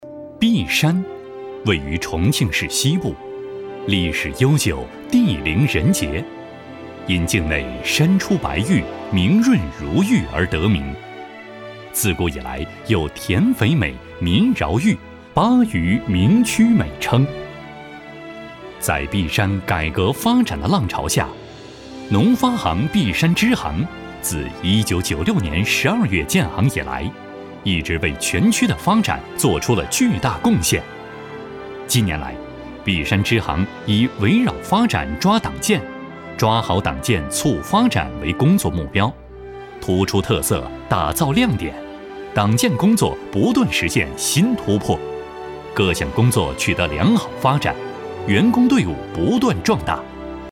稳重磁性 企业专题,人物专题,医疗专题,学校专题,产品解说,警示教育,规划总结配音
磁性稳重男中音，年轻活力、亲和温馨。